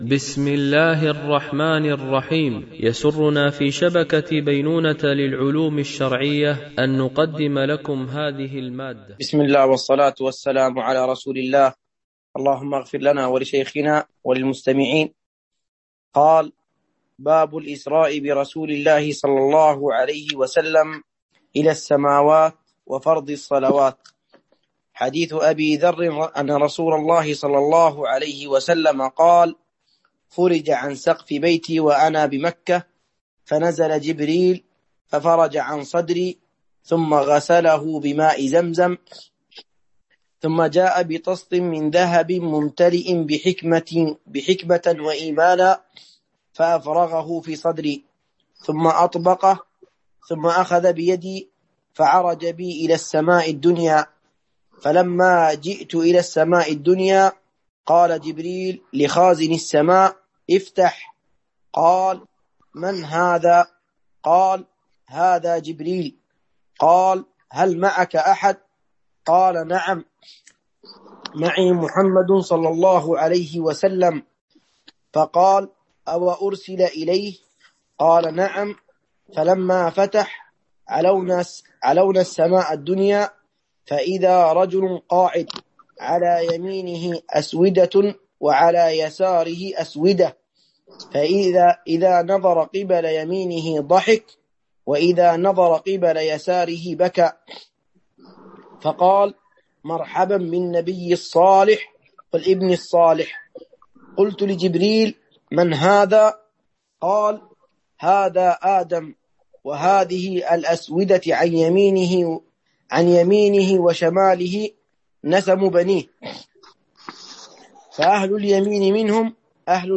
التنسيق: MP3 Mono 22kHz 64Kbps (VBR)